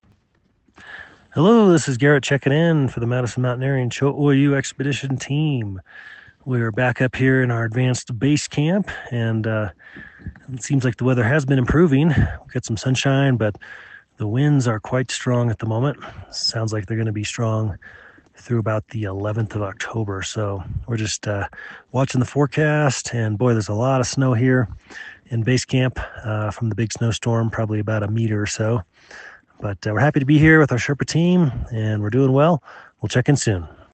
checks in with this update from advanced base camp on Cho Oyu: